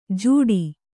♪ jūḍi